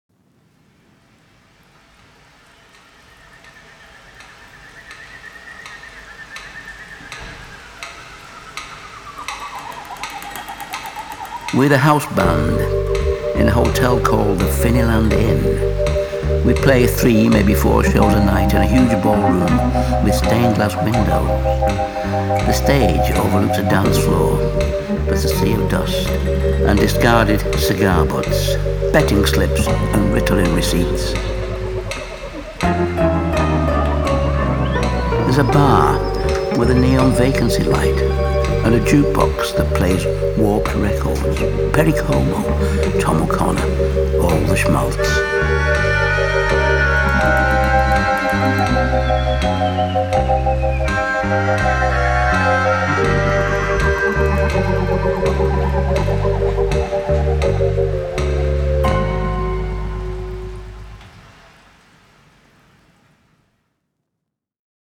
Genre : Alternative, Indie Rock